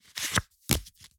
card_flip.mp3